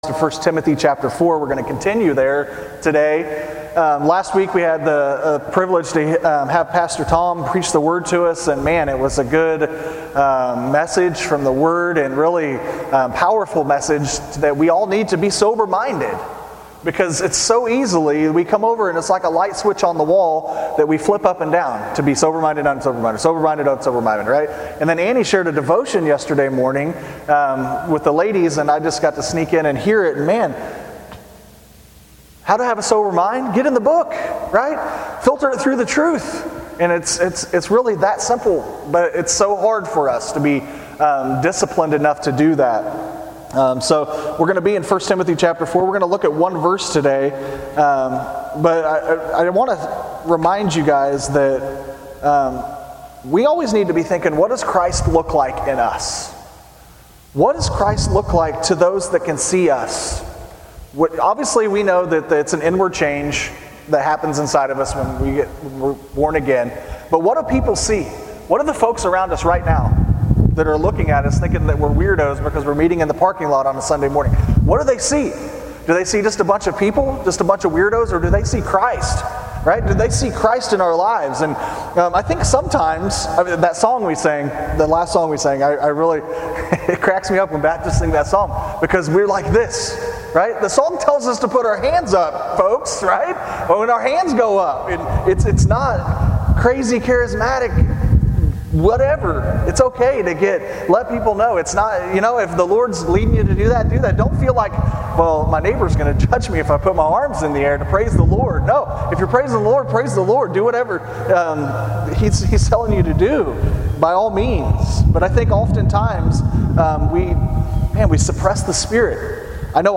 Sermon on 1Timothy 4:12, and the instruction to be an example of the believers in word, conversation, charity, spirit, faith, and purity.